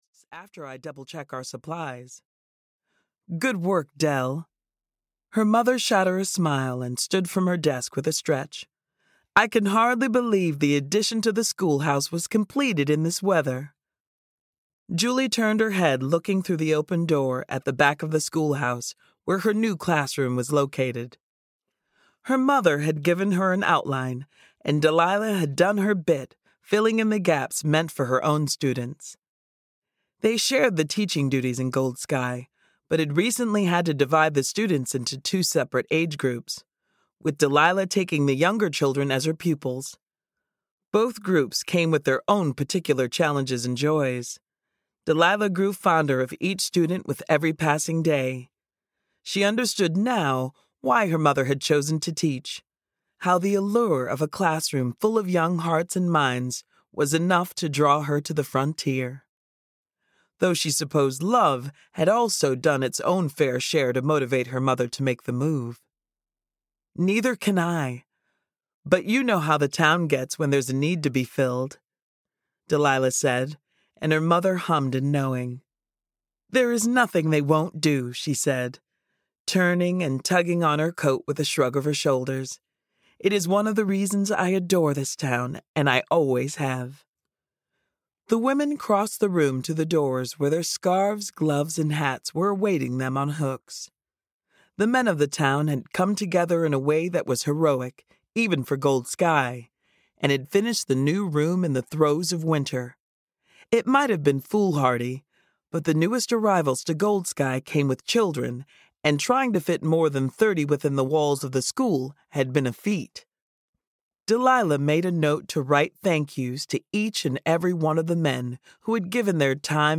Pride and Passion (EN) audiokniha
Ukázka z knihy